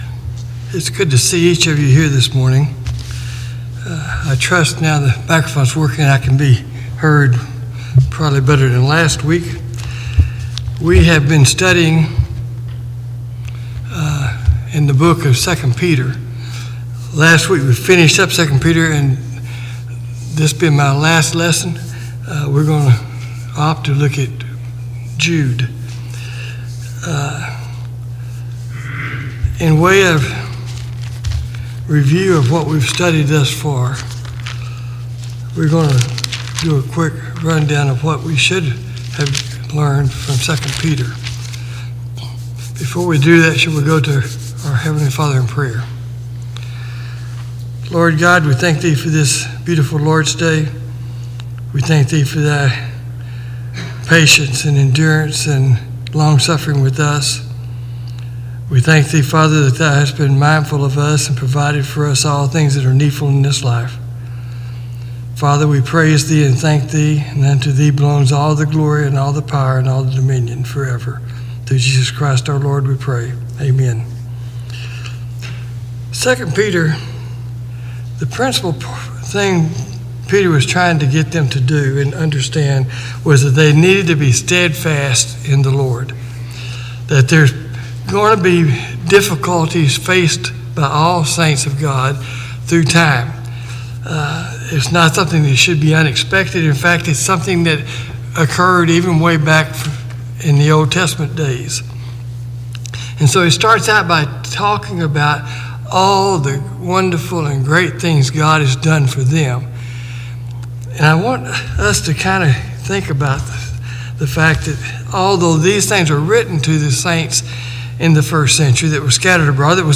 Passage: Jude 1:1-25 Service Type: Sunday Morning Bible Class